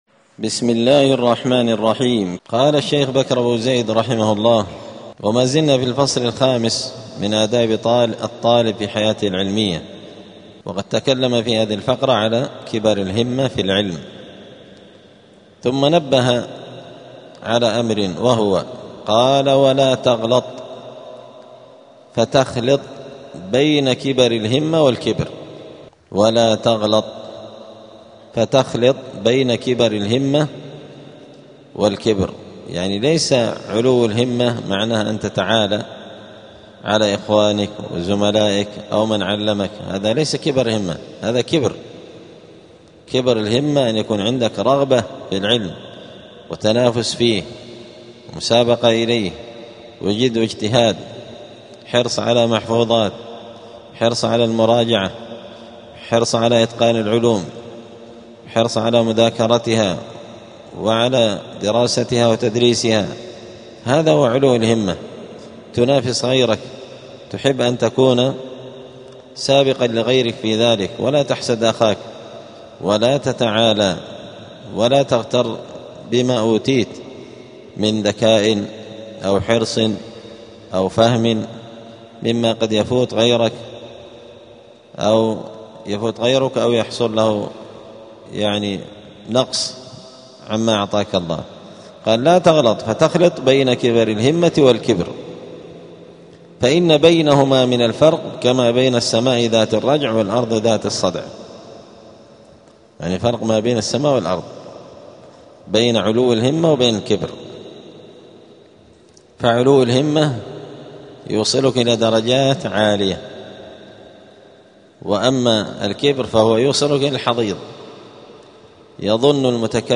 الجمعة 9 جمادى الأولى 1447 هــــ | الدروس، حلية طالب العلم، دروس الآداب | شارك بتعليقك | 6 المشاهدات
دار الحديث السلفية بمسجد الفرقان قشن المهرة اليمن